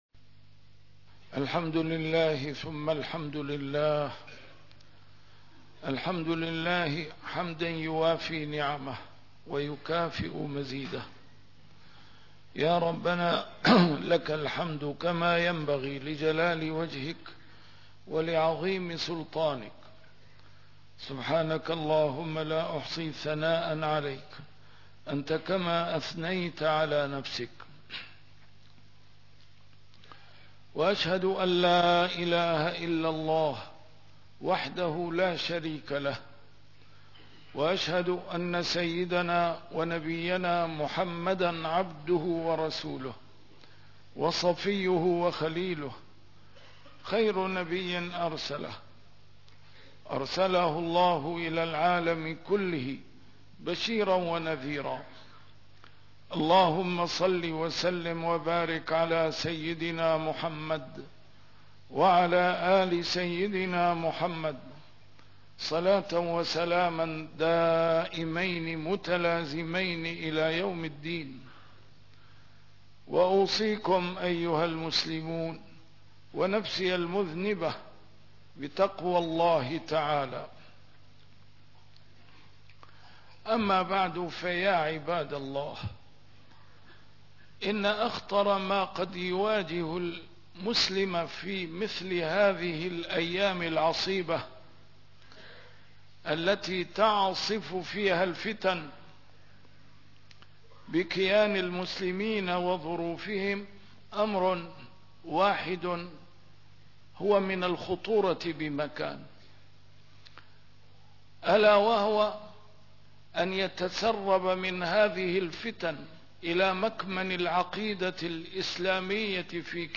A MARTYR SCHOLAR: IMAM MUHAMMAD SAEED RAMADAN AL-BOUTI - الخطب - عاصم عقيدة المسلم في هذه الفتن